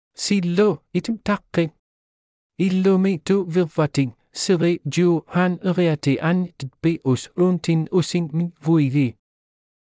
Taqqiq — Male Inuktitut AI voice
Taqqiq is a male AI voice for Inuktitut (Latin, Canada).
Voice sample
Listen to Taqqiq's male Inuktitut voice.
Male